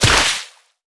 water_spell_impact_hit_05.wav